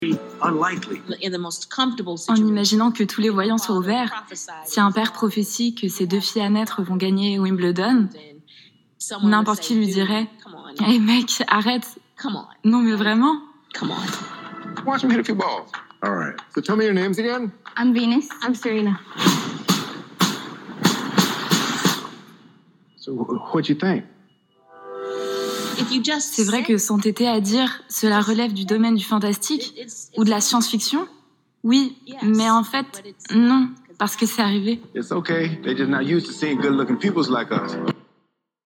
PILS VOIX OFF
17 - 27 ans